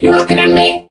mech_crow_get_hit_05.ogg